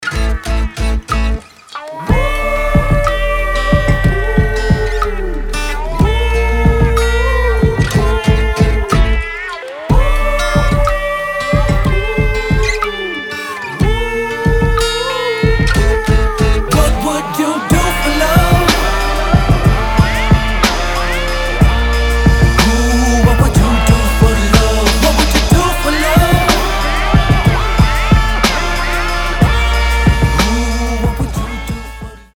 • Качество: 320, Stereo
гитара
мужской вокал
Хип-хоп